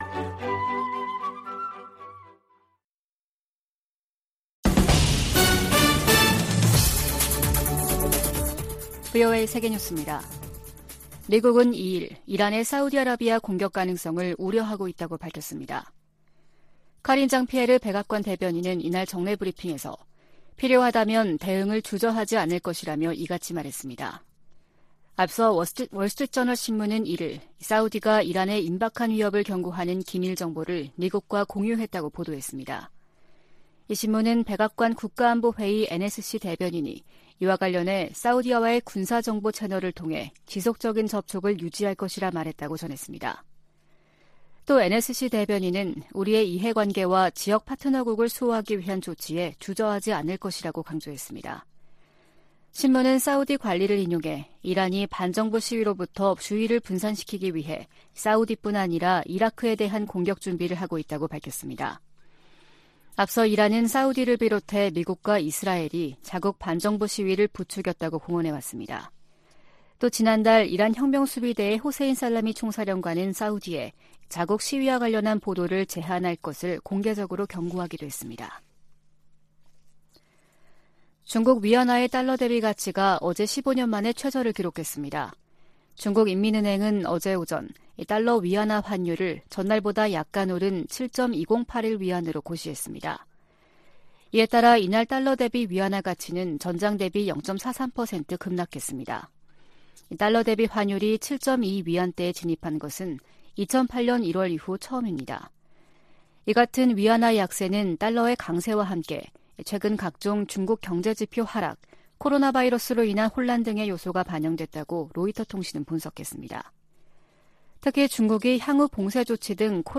VOA 한국어 아침 뉴스 프로그램 '워싱턴 뉴스 광장' 2022년 11월 2일 방송입니다.